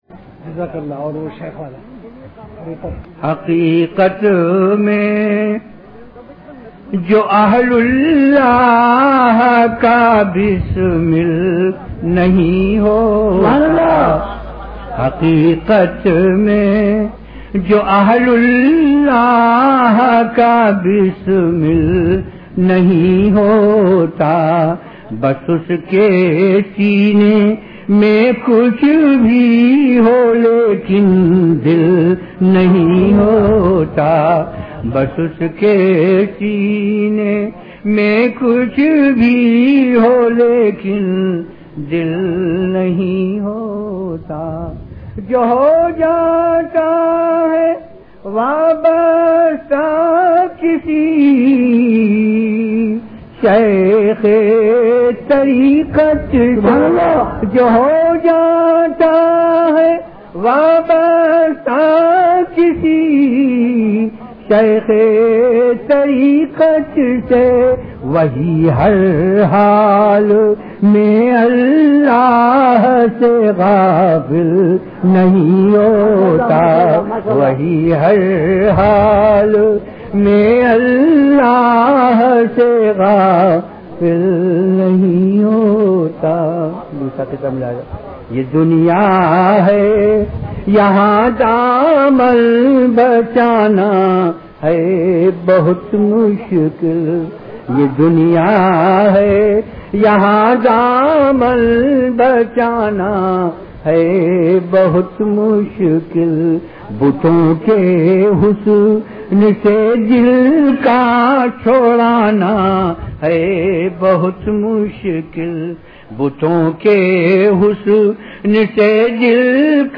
CategoryBayanat
VenueKhanqah Imdadia Ashrafia
Event / TimeAfter Isha Prayer